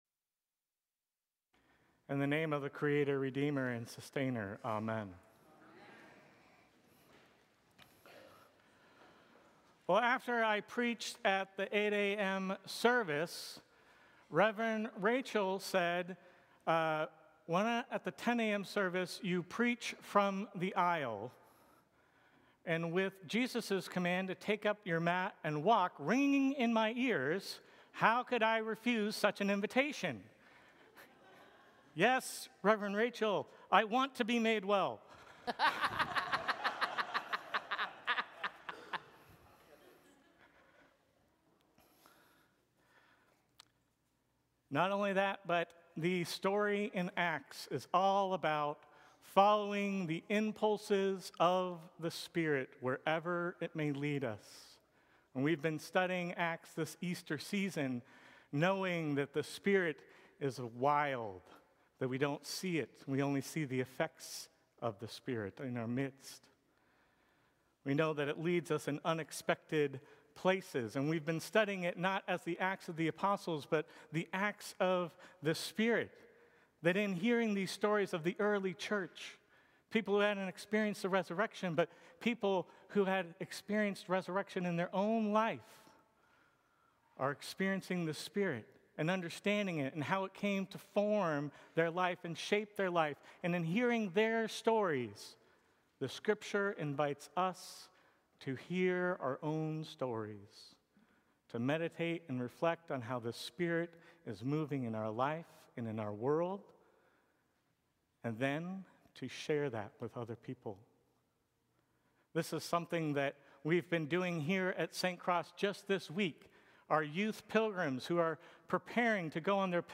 Sermons from St. Cross Episcopal Church Sixth Sunday of Easter May 25 2025 | 00:15:27 1x 00:00 / 00:15:27 Subscribe Share Apple Podcasts Spotify Overcast RSS Feed Share Link Embed